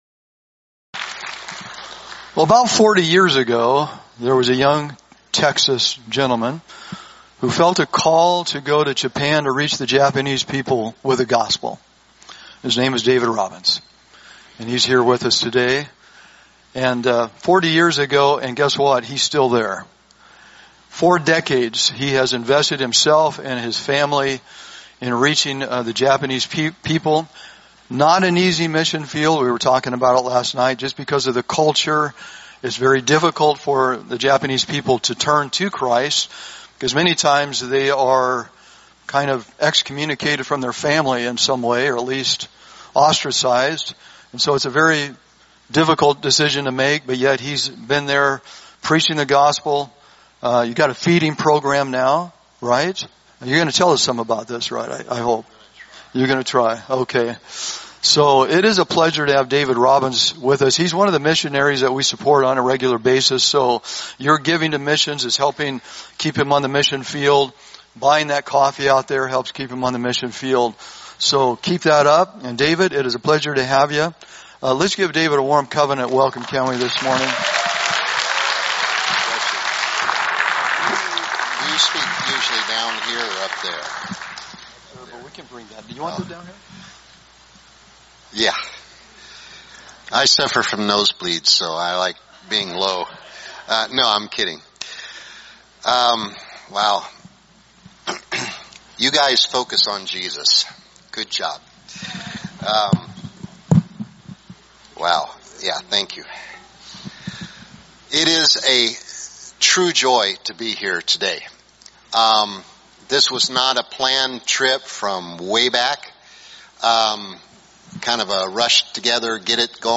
Sermons | Covenant Christian Church